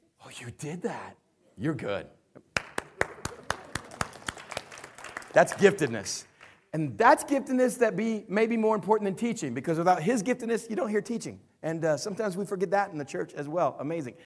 The sample file is a small excerpt from a conference I was volunteering at.
This last clip is how the recording sounded after I fixed the problem on site.